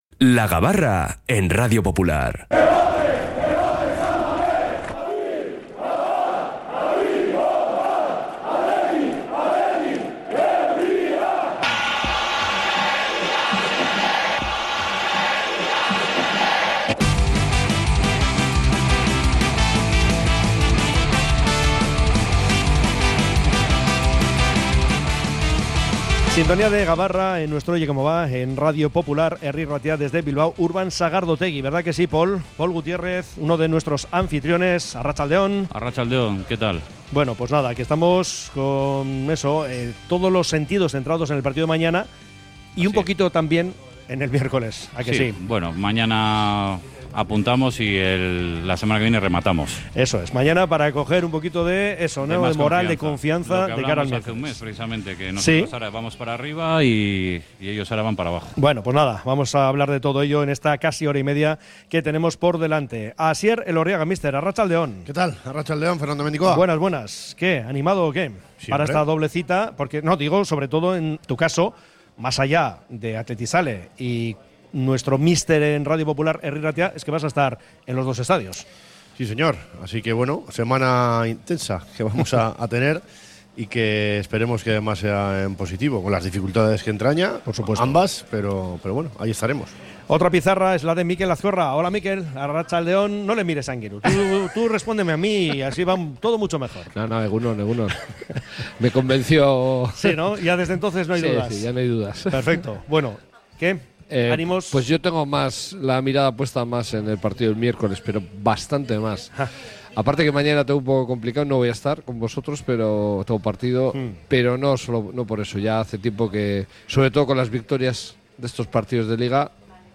Desde Bilbao Urban Sagardotegi hemos analizado, como cada viernes, toda la actualidad rojiblanca